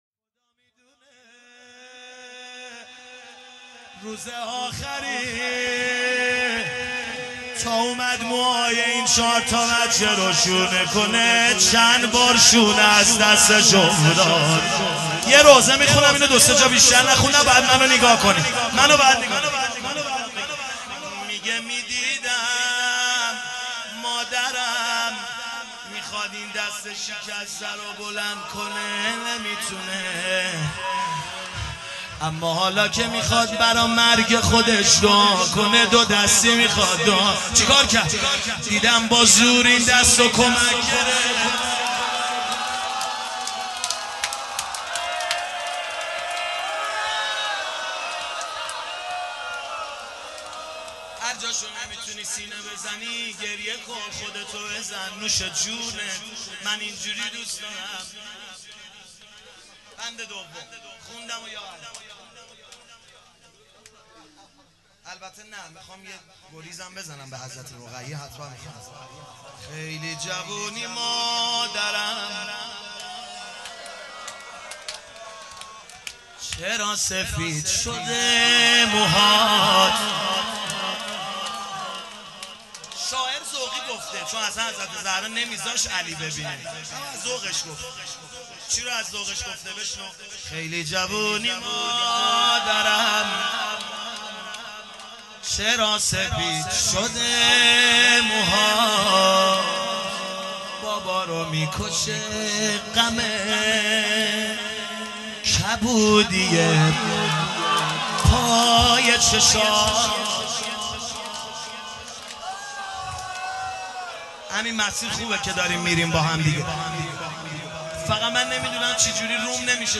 دانلود مداحی خیلی جوونی مادرم چرا سفید شده موهات - دانلود ریمیکس و آهنگ جدید
روضه خوانی و مناجات جانسوز فاطمیه 1396